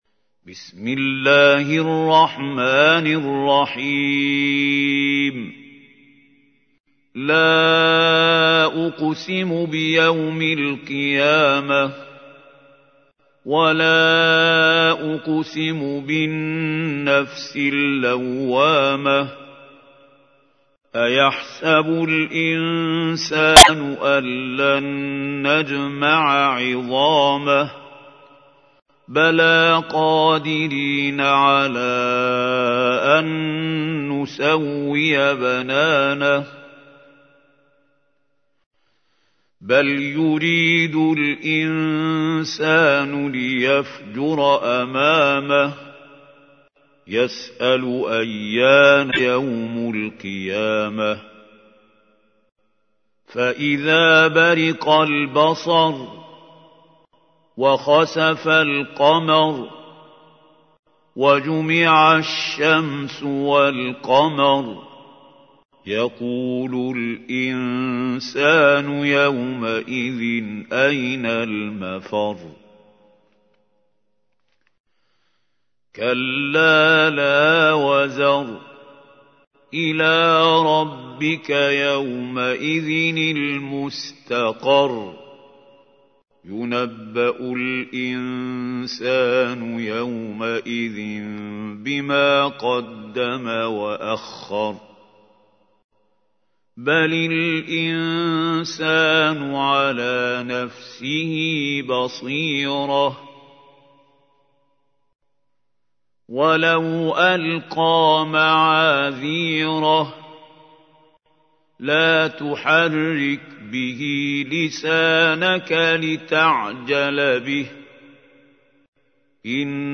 تحميل : 75. سورة القيامة / القارئ محمود خليل الحصري / القرآن الكريم / موقع يا حسين